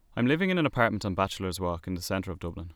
Dublin accent